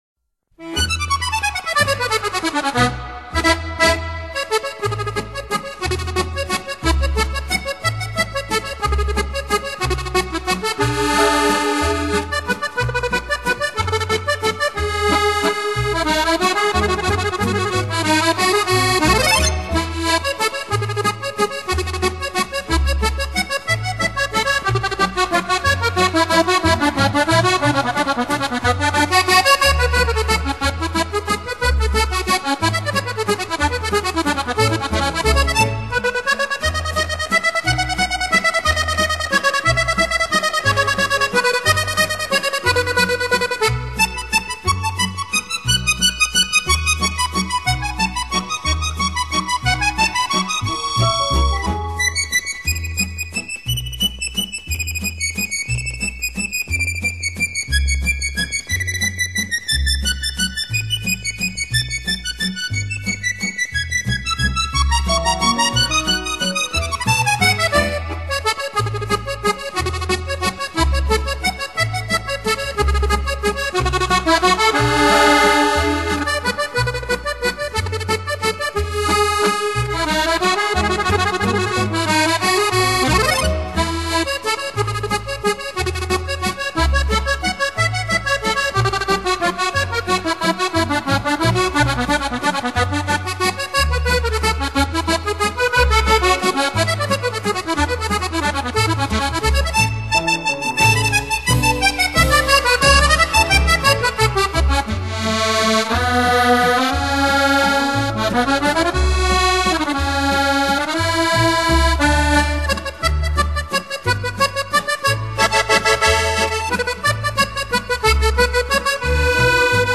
Vals-acordeón-música-francesa.mp3